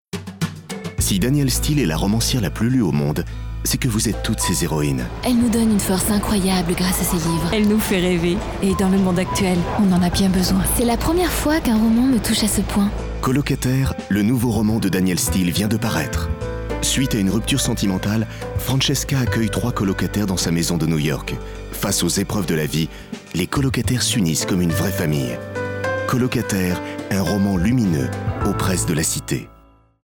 Prestation voix-off pour "Colocataires" : ton posé, naturel et conteur
Voix posée et introductive.
Pour « Colocataires », j’ai opté pour un ton posé, naturel, un peu conteur, qui correspondait parfaitement à l’atmosphère du livre.